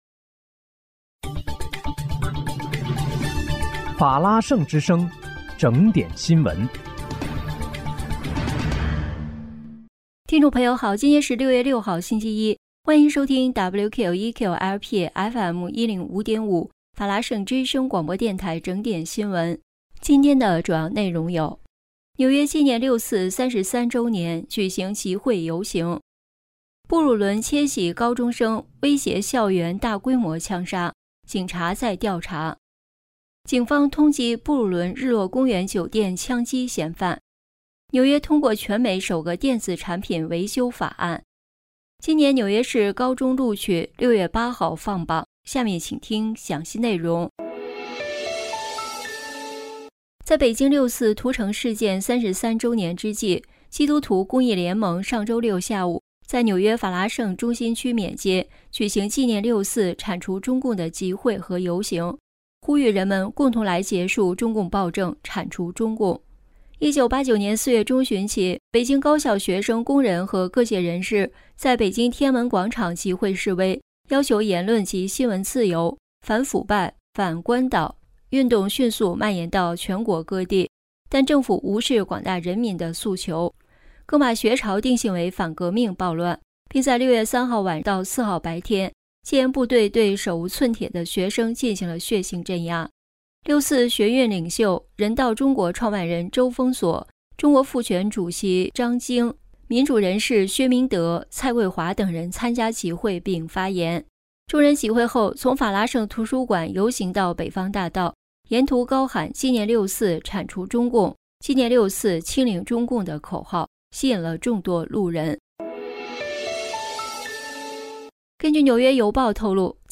6月6日（星期一）纽约整点新闻